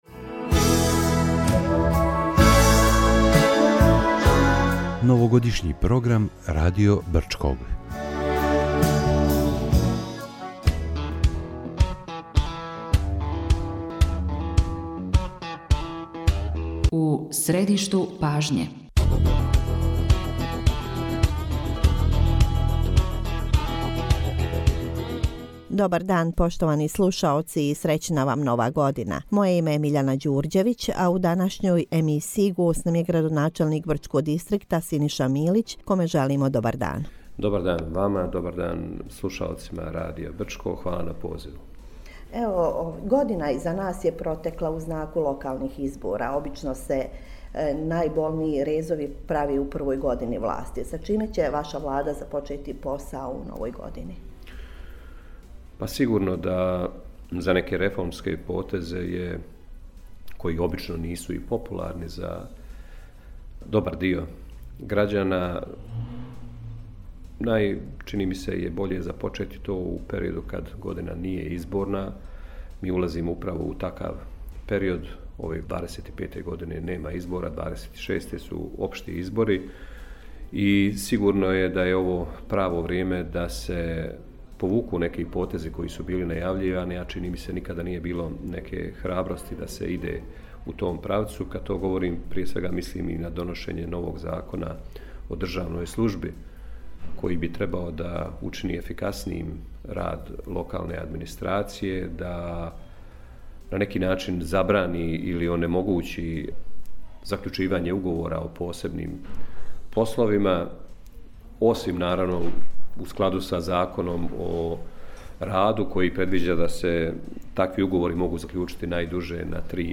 У Новогодишњој емисији са градоначелником Дистрикта Брчко смо разговарали о приоритетима у новој 2025. години.